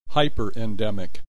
click this icon to hear the preceding term pronounced.